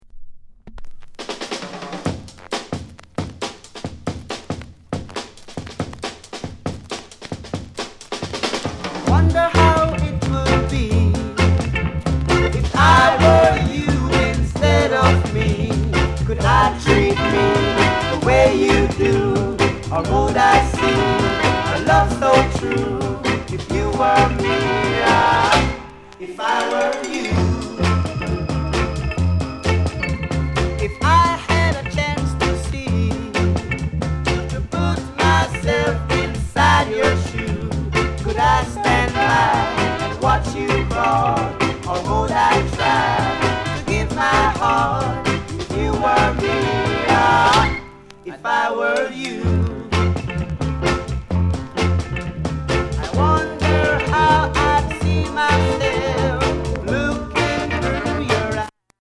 SOUND CONDITION A SIDE VG(OK)
NICE ROCKSTEADY